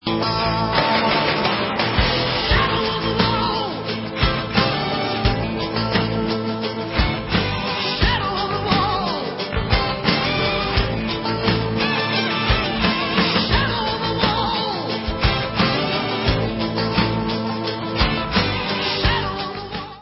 Original album remastered